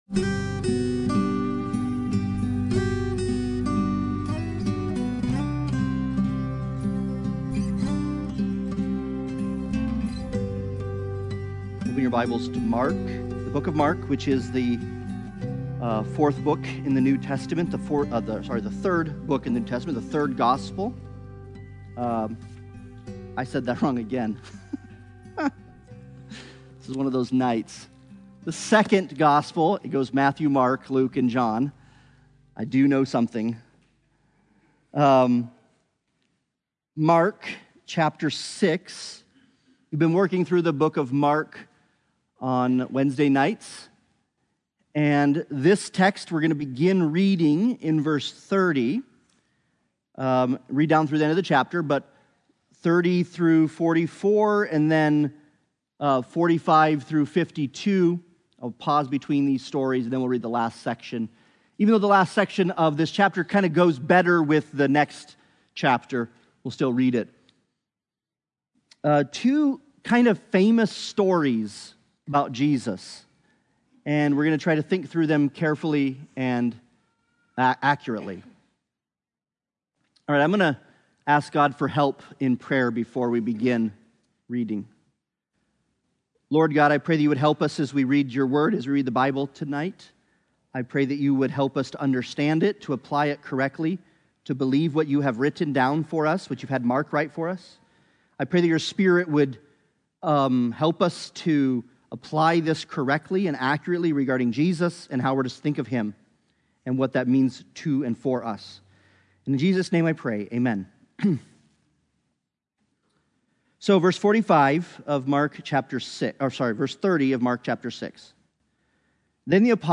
The Gospel According to Mark Passage: Mark 6:30-52 Service Type: Sunday Bible Study « Wake Up